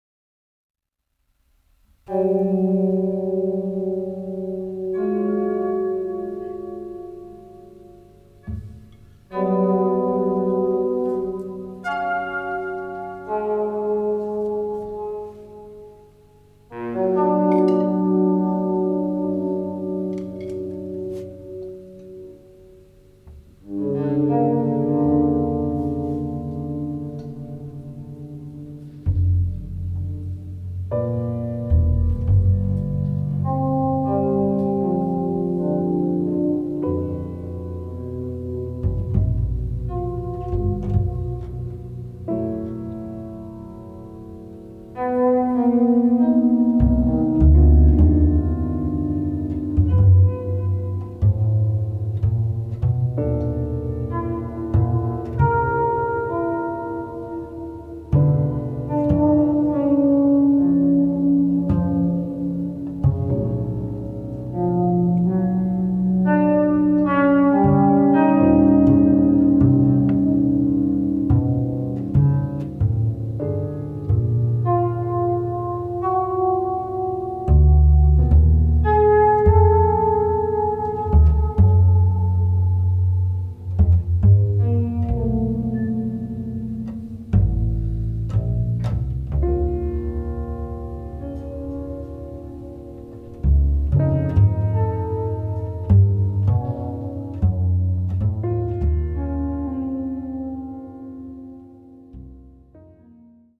pianoforte
chitarra e synth
Contrabbasso
percussioni